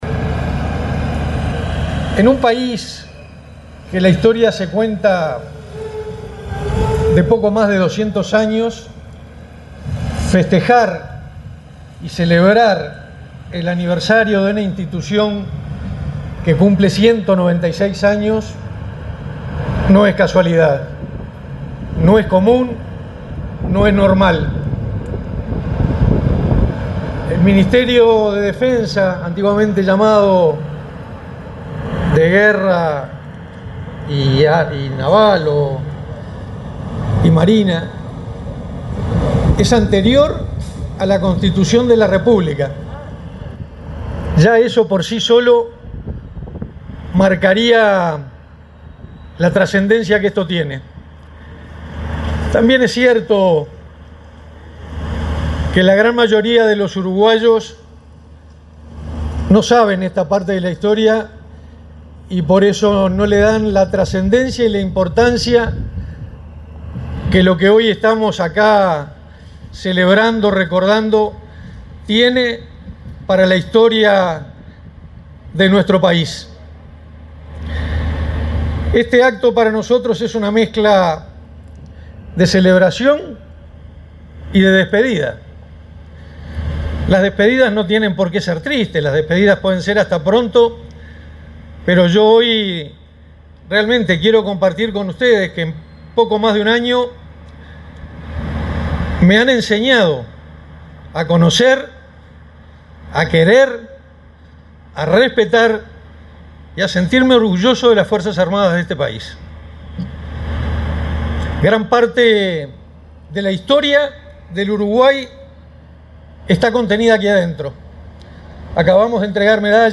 Palabras del ministro de Defensa Nacional, Armando Castaingdebat
Este viernes 20, el ministro de Defensa Nacional, Armando Castaingdebat, encabezó la ceremonia del 196.º aniversario de la creación del Ministerio de